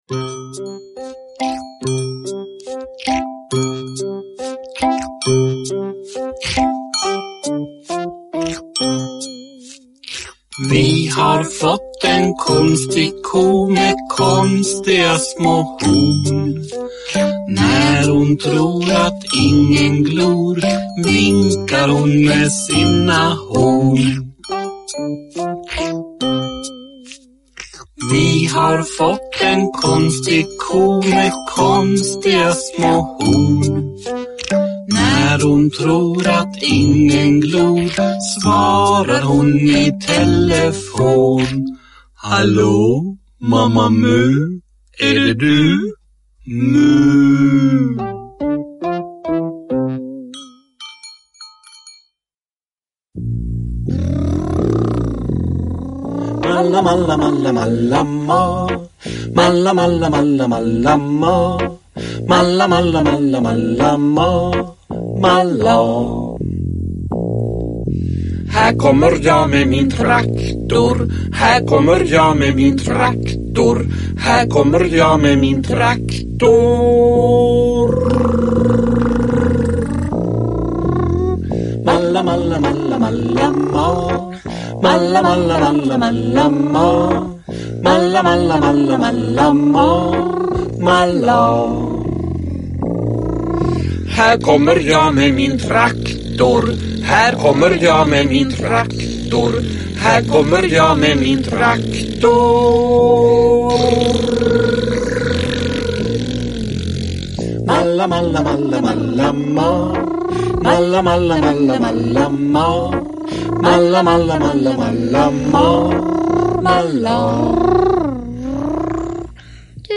Mamma Mu gungar – Ljudbok – Laddas ner
Uppläsare: Jujja Wieslander